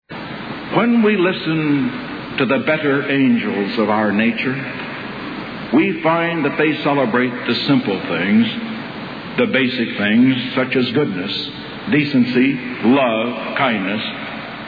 Tags: Travel Asyndeton Figure of Speech Brachylogia Speeches